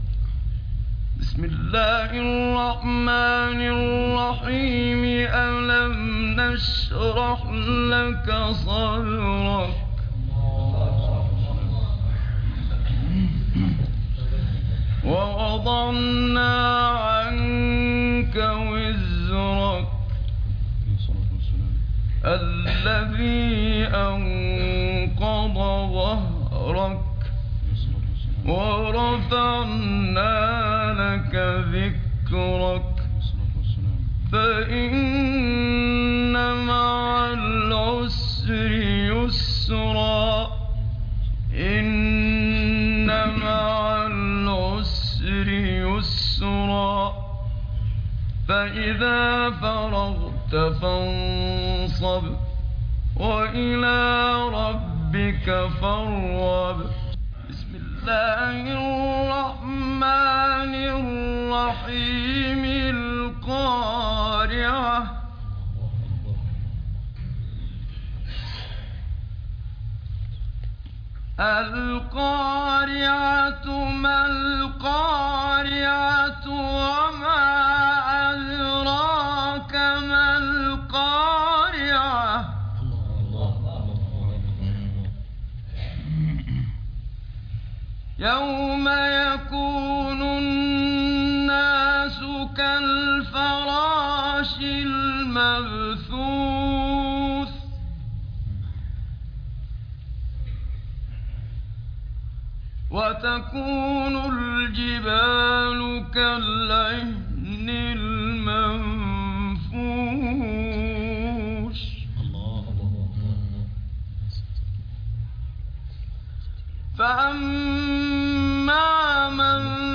عنوان المادة 093 الضحى الشرح القارعة الكوثر الفاتحة تلاوات نادرة بصوت الشيخ محمد صديق المنشاوي